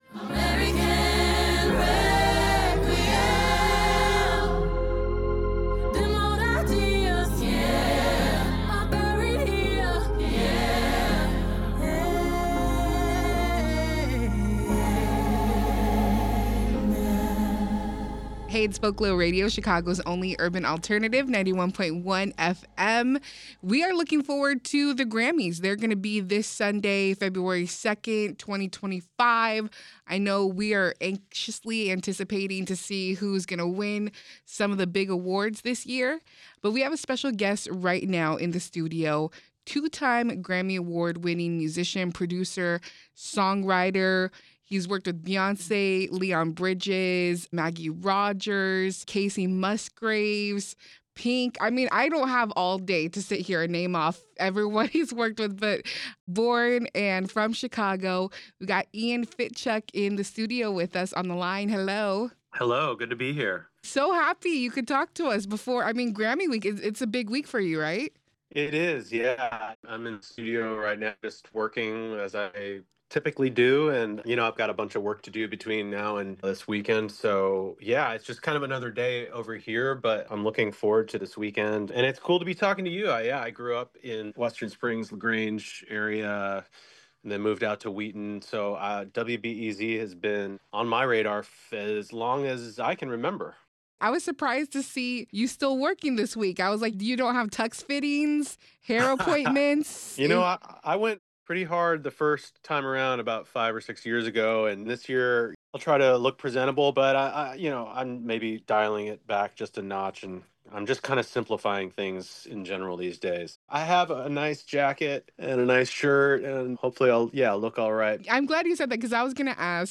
This segment originally aired on Vocalo Radio 91.1 FM on Tuesday, Jan. 28.